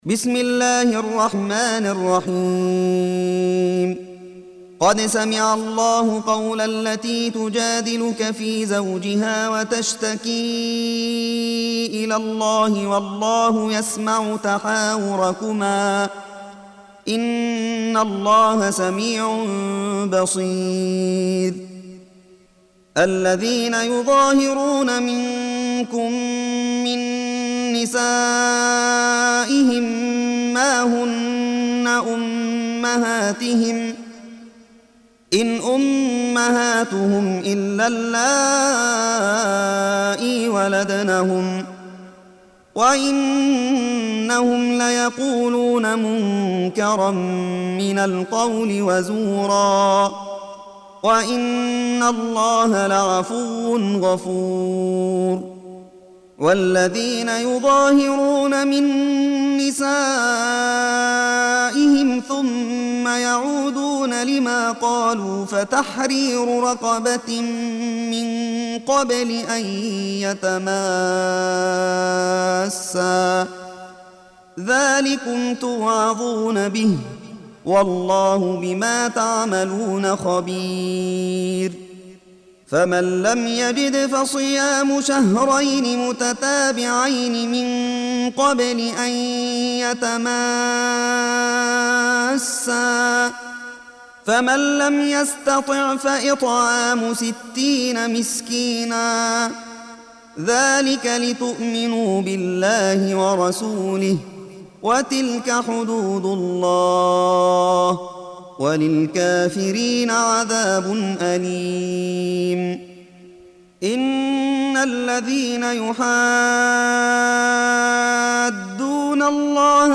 58. Surah Al-Muj�dilah سورة المجادلة Audio Quran Tarteel Recitation
حفص عن عاصم Hafs for Assem